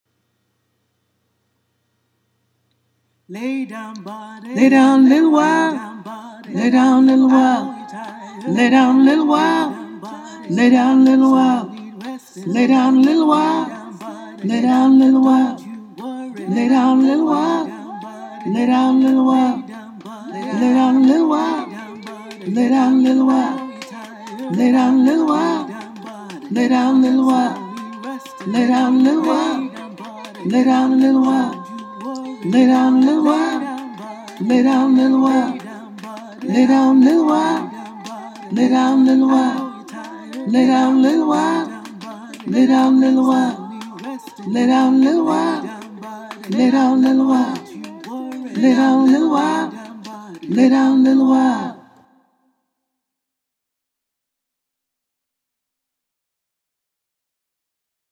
Lay-Down-Body-Alto.mp3